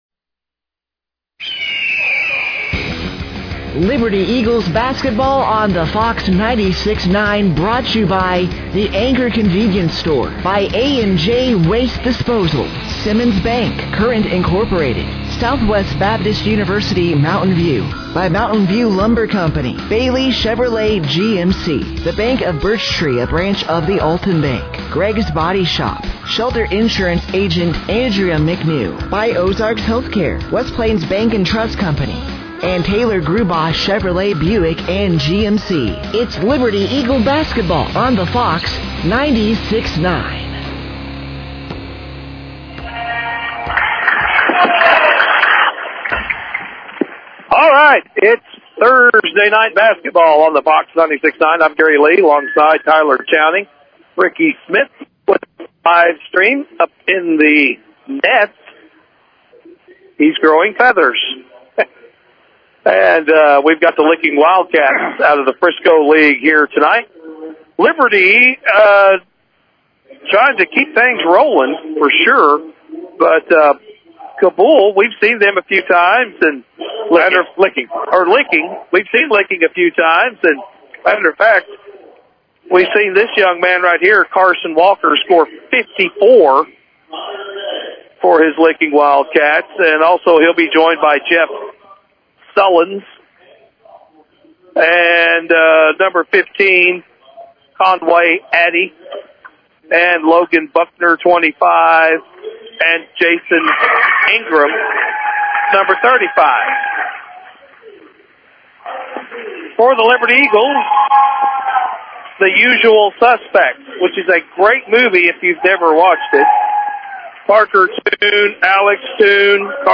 Game Audio Below: The Liberty Eagles faced the (12-10) Licking Wildcats at home on Thursday night.